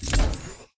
footsteps